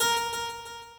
harp2.ogg